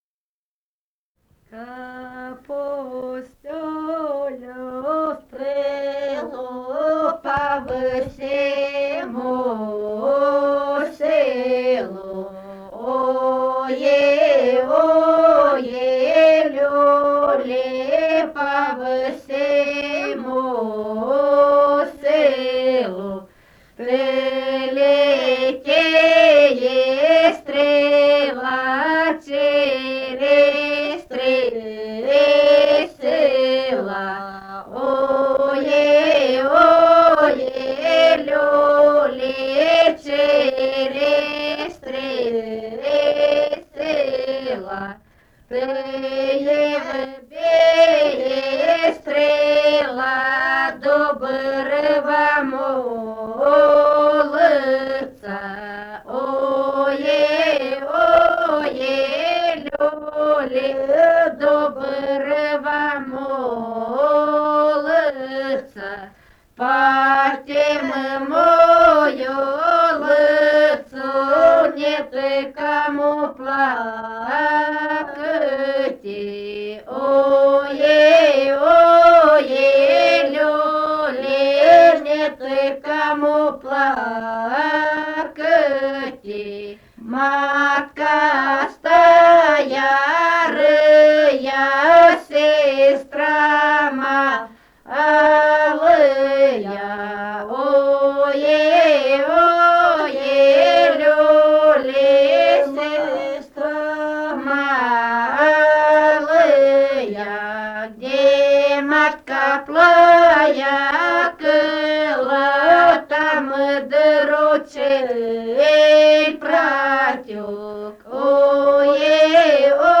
«А пустю я стрелу» (хоровод-шествие на масленицу).
Румыния, с. Переправа, 1967 г. И0974-05